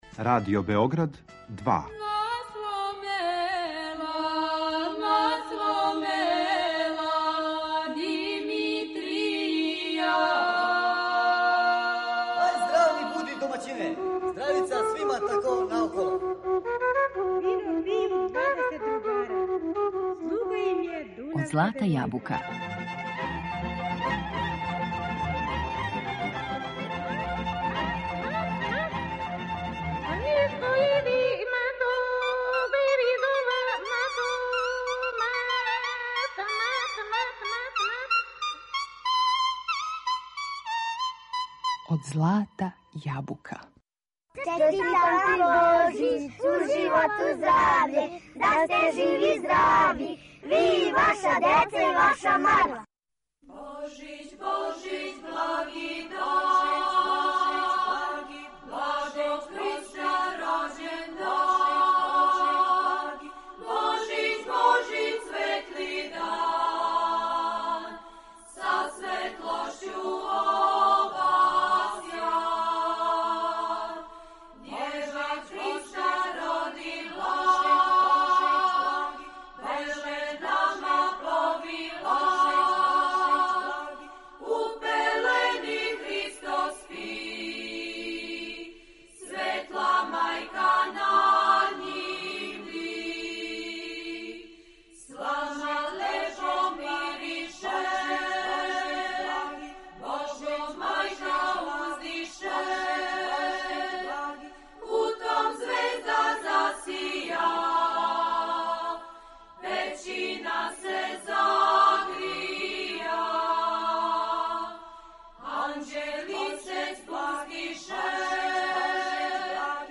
У данашњој емисији Од злата јабука, уз божићне песме и лепо расположење, провешћемо вас кроз један мали подсетник – како то српски обичај налаже и како је у традицији и народу забележено – шта би ваљало радити на данашњи дан и зашто...